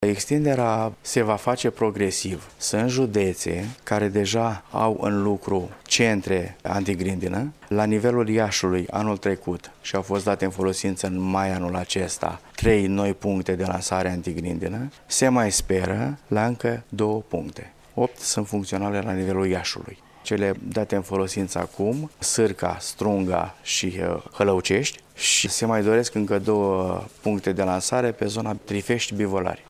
Sistemul antigrindină din judeţul Iaşi se va extinde şi în zonele localităţilor Bivolari şi Trifeşti a anunţat astăzi, într-o conferinţă de presă, directorul Direcţiei pentru Agricultură Iaşi, Laurenţiu Precup.
Directorul Direcţiei pentru Agricultură Iaşi a precizat că suprafaţa arabilă a judeţului este de aproximativ 255 de mii de hectare: